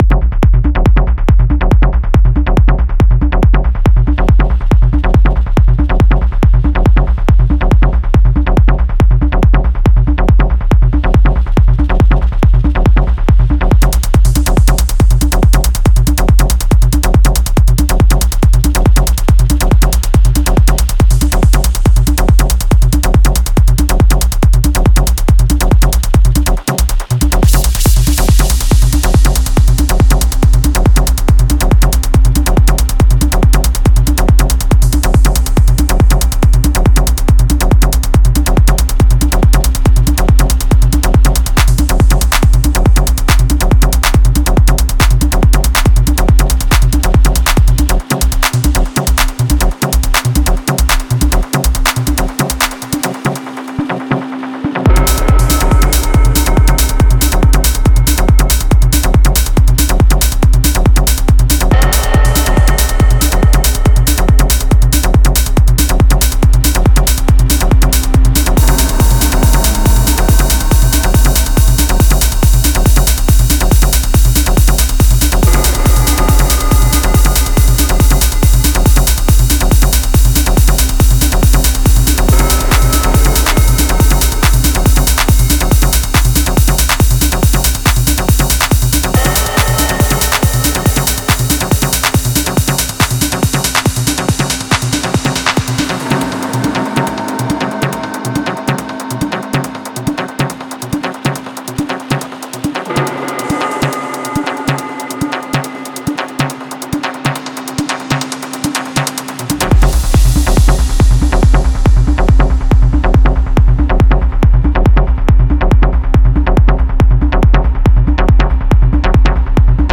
Genre:Techno
催眠的なテクノサンプルパックです。
デモサウンドはコチラ↓